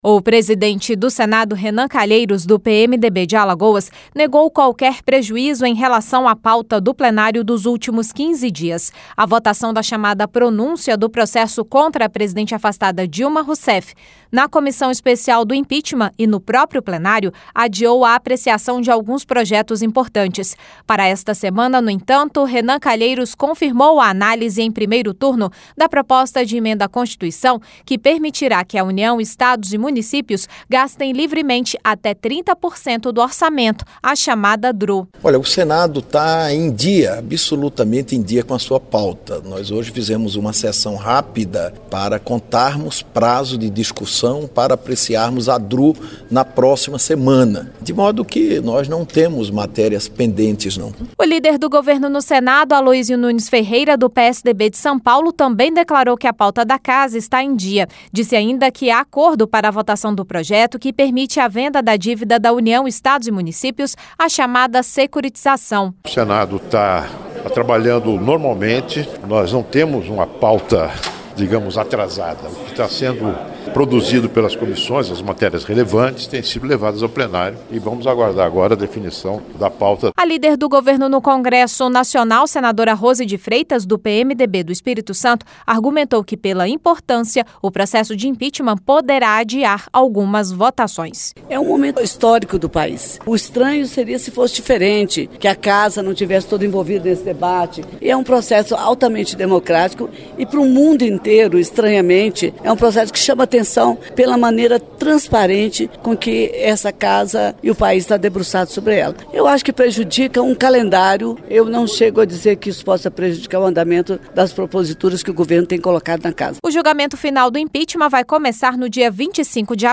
Discursos